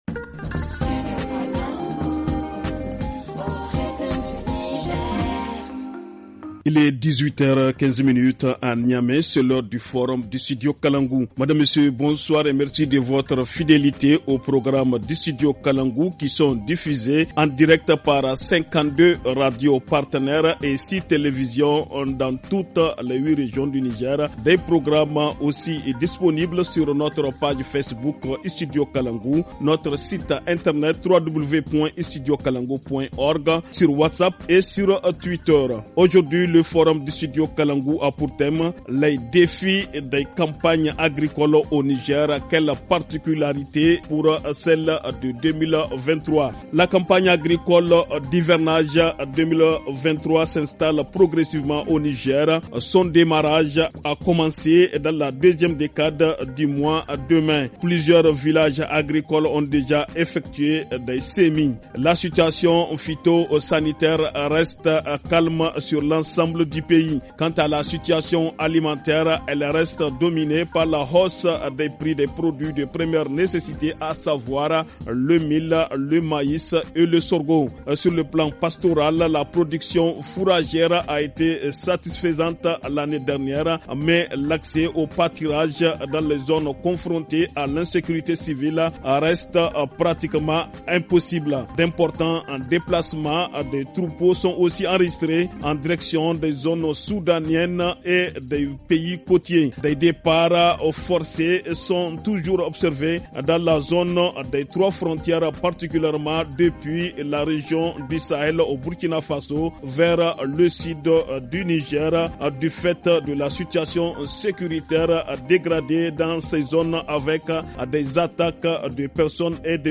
Au téléphone
Le forum en français